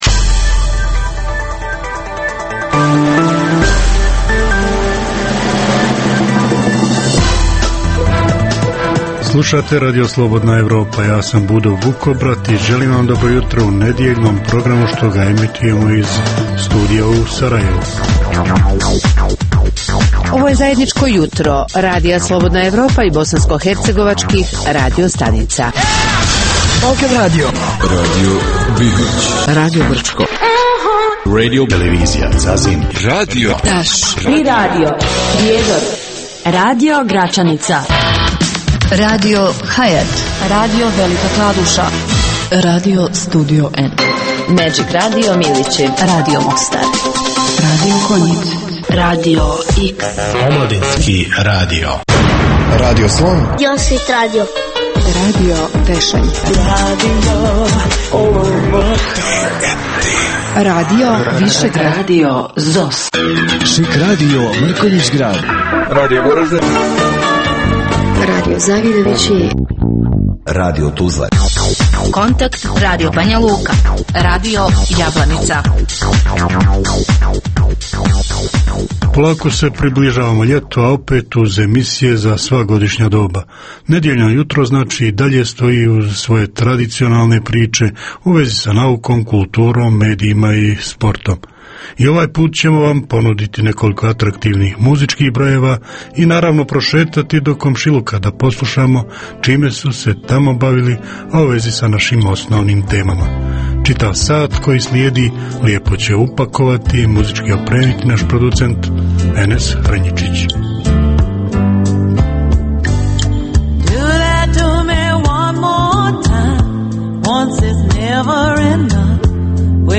Jutarnji program namijenjen slušaocima u Bosni i Hercegovini.